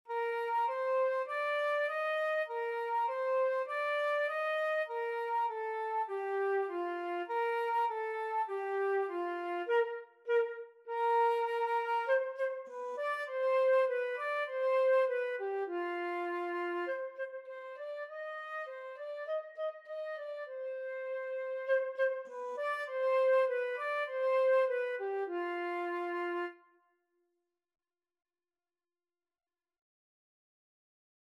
4/4 (View more 4/4 Music)
F5-Eb6
Beginners Level: Recommended for Beginners
Flute  (View more Beginners Flute Music)
Classical (View more Classical Flute Music)